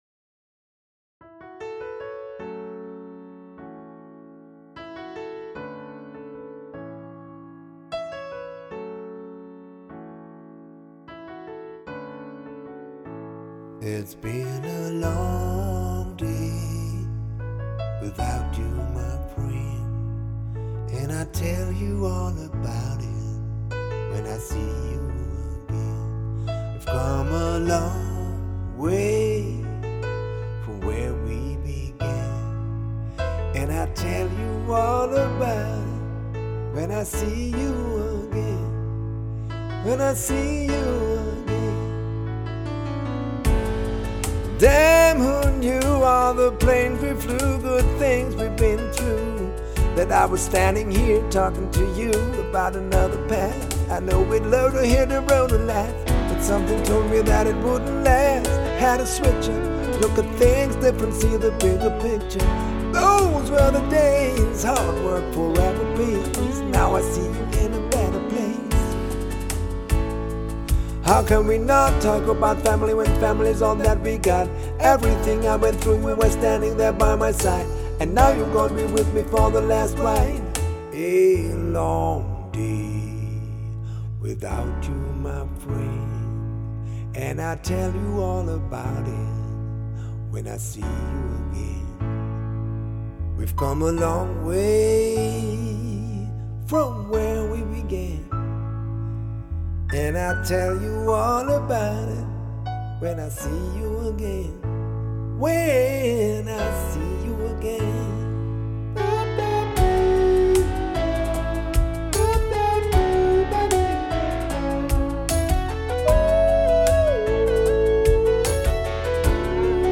- professionelles vielseitiges Klavierspiel